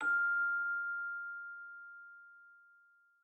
celesta1_5.ogg